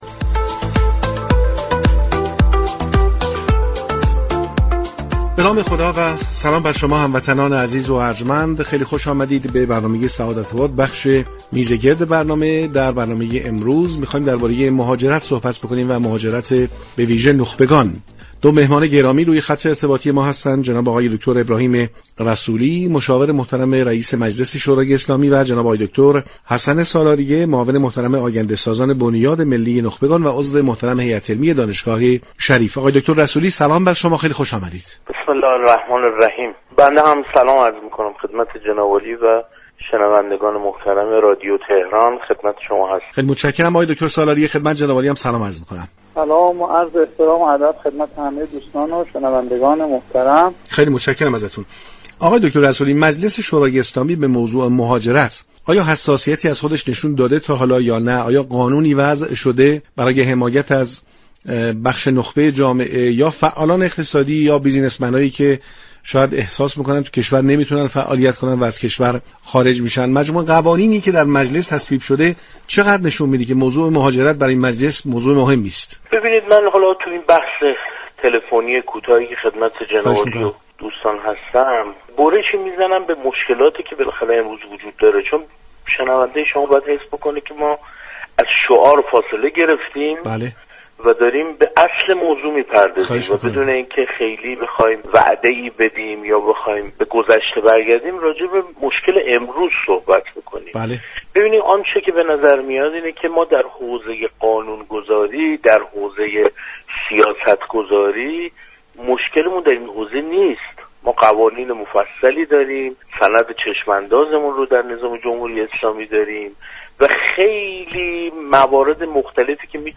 بررسی موضوع مهاجرت نخبگان در میزگرد سعادت‌آباد+فایل صوتی